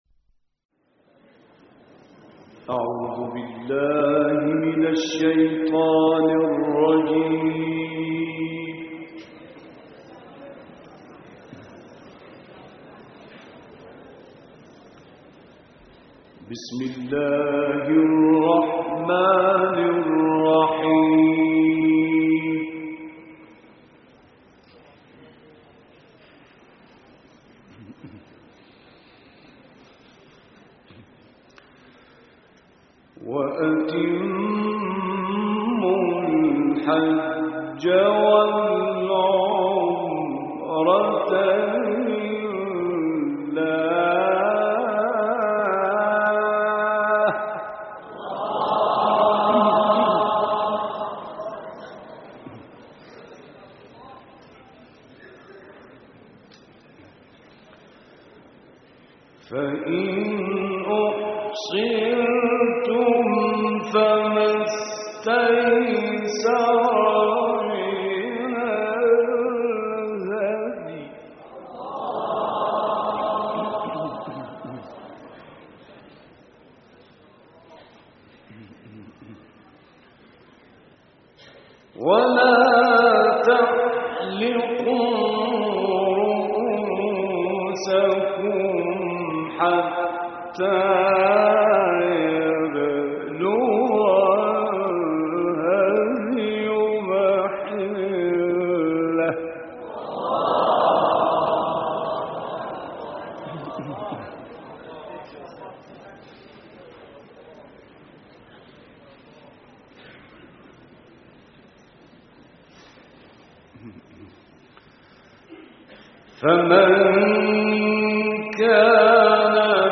دانلود قرائت سوره بقره آیات 196 تا 203 - استاد راغب مصطفی غلوش